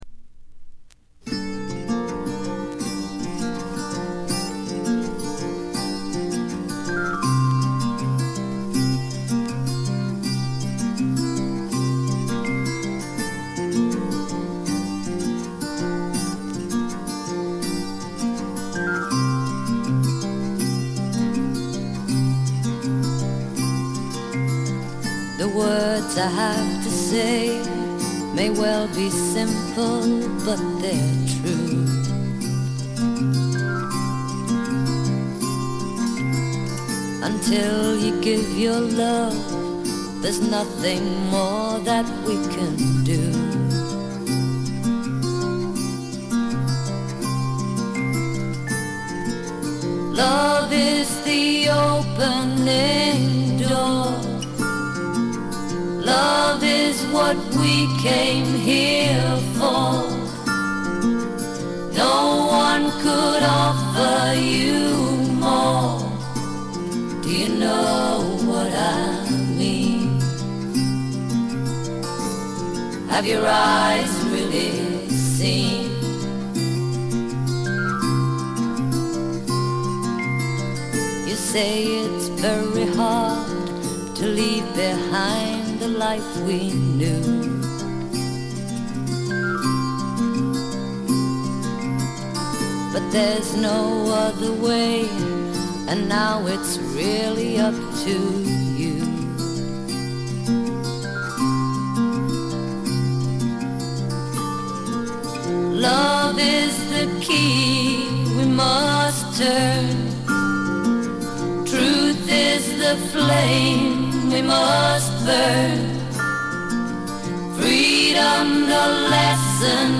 わずかなノイズ感のみ。
試聴曲は現品からの取り込み音源です。